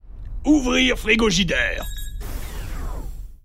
ouvrir_frigogidere.mp3